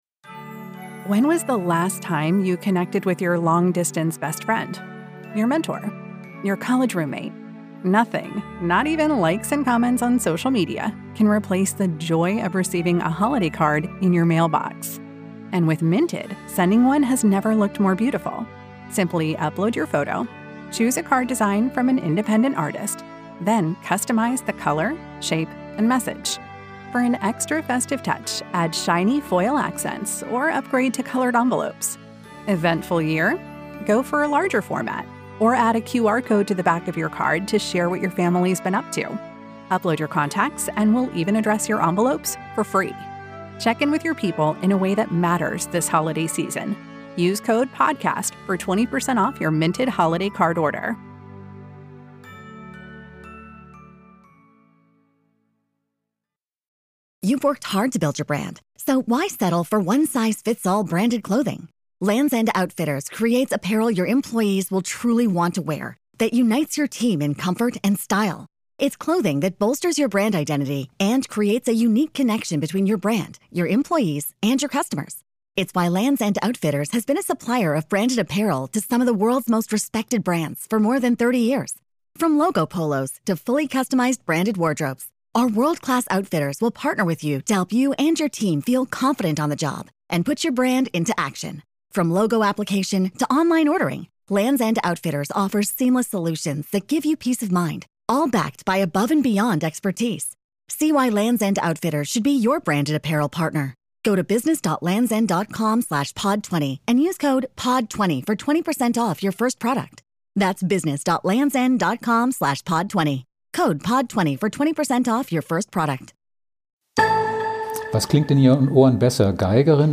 Wie es kam, dass Janine Jansen in London auf zwölf Stradivaris spielen durfte. Ein Gespräch über diesen Luxus, über Karriere und Burnout